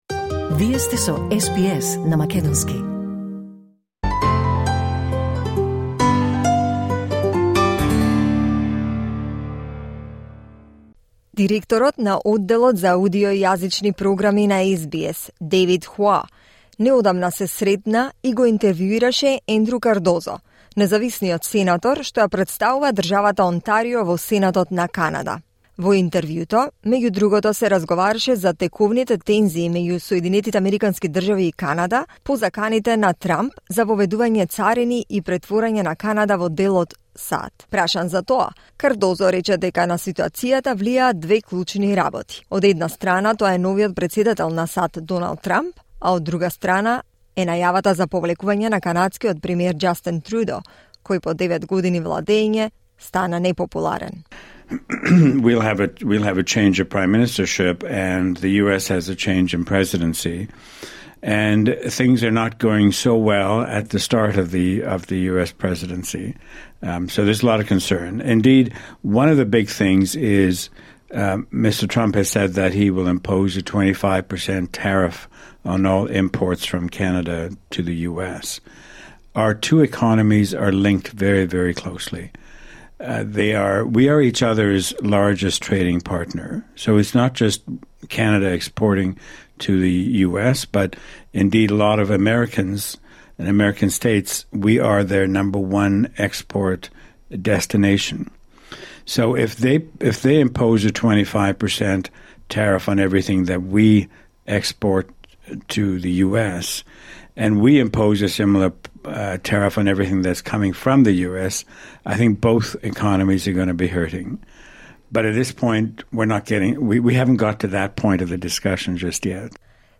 разговараше со независниот сенатор од Онтарио, Канада, Ендру Кардозо.